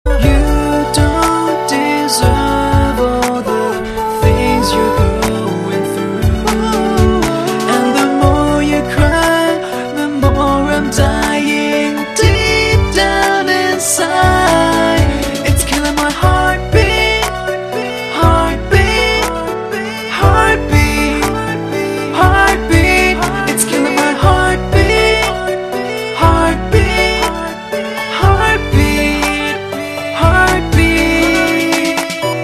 M4R铃声, MP3铃声, 欧美歌曲 42 首发日期：2018-05-14 05:31 星期一